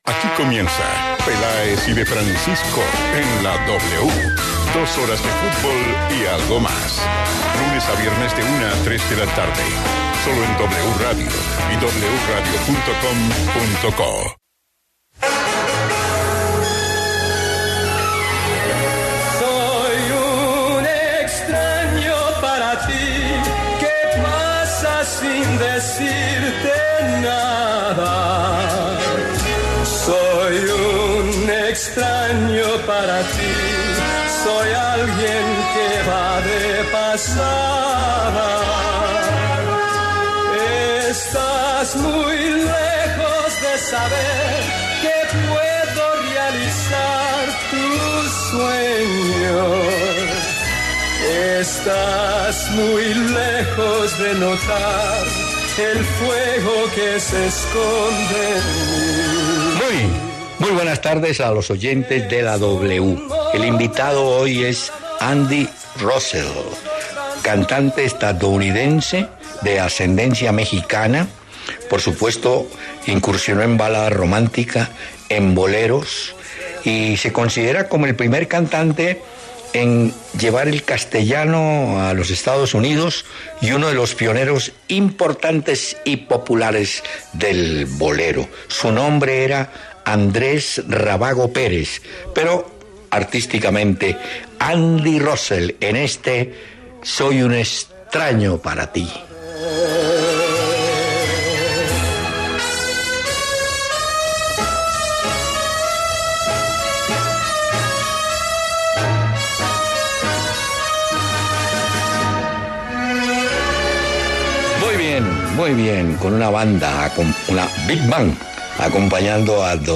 Hernán Peláez y Martín De Francisco analizaron el juego entre Colombia y Venezuela por la última jornada de las Eliminatorias al Mundial de Qatar 2022.